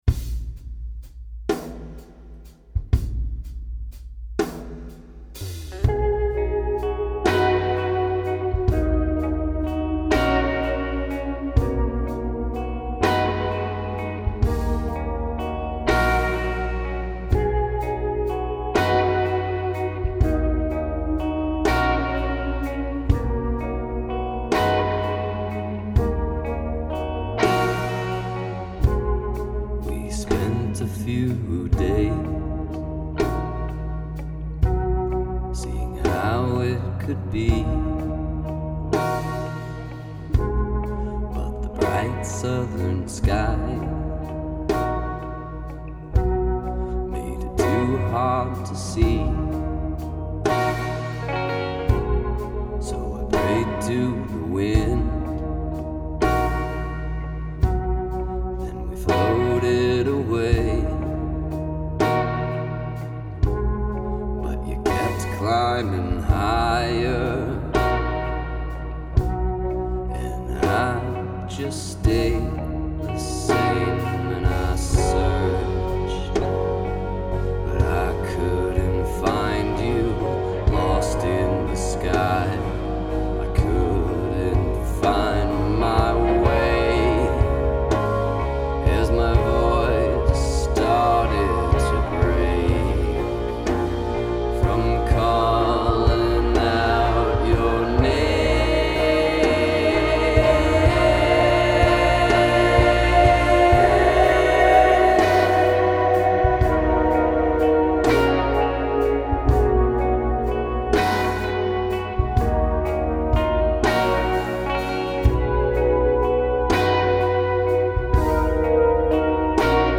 dark, singer/songwriter tracks